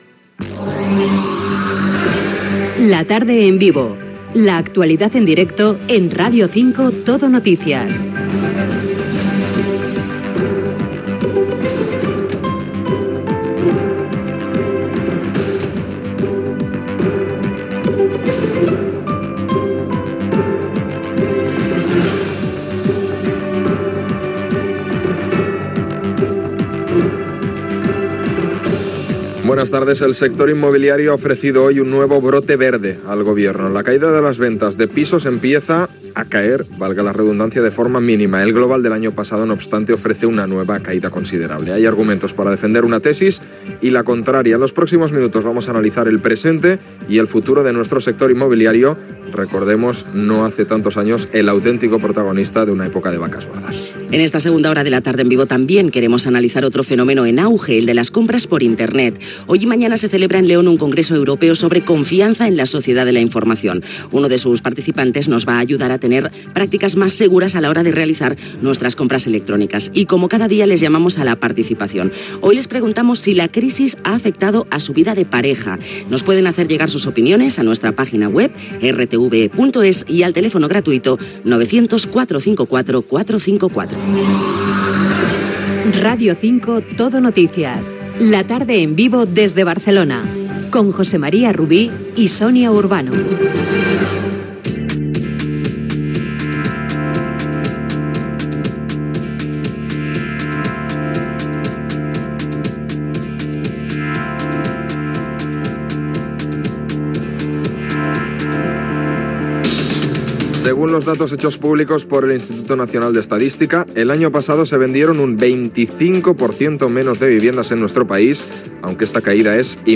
Indicatiu del programa, sumari dels temes d'aquella hora, indicatiu, el negoci immobiliari
Info-entreteniment